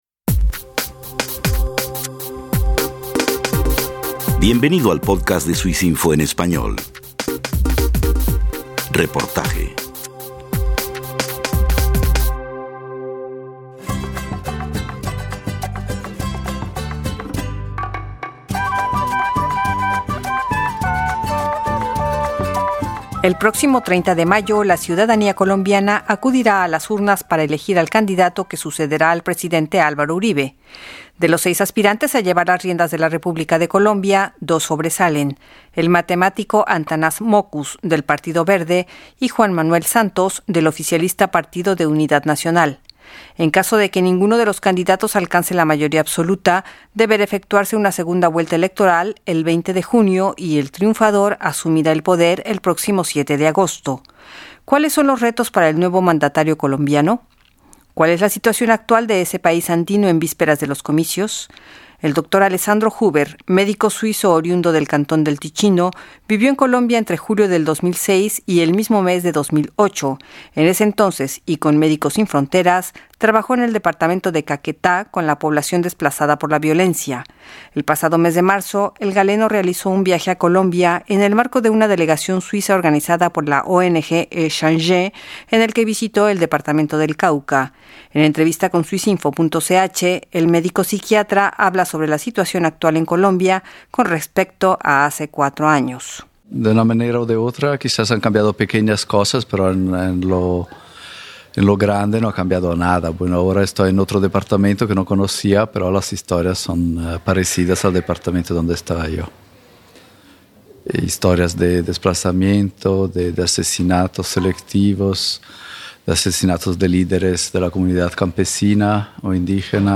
Análisis